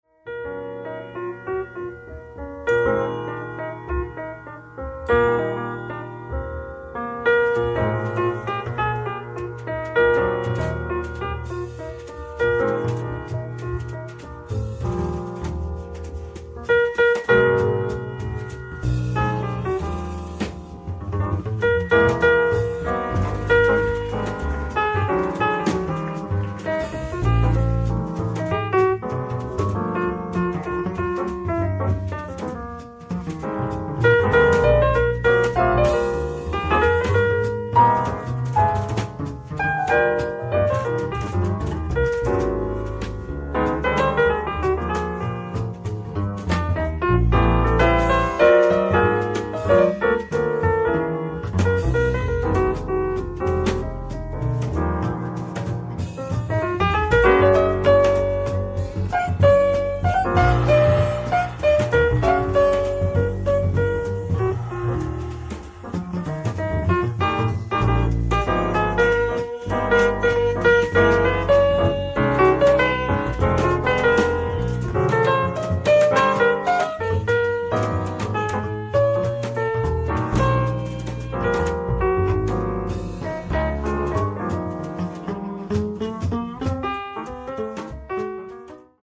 ライブ・アット・アンテイーブ・ジャズ、ジュアン・レ・パン、フランス 07/19/1996
※試聴用に実際より音質を落としています。
Disc 1(Re-Braodcaste Version)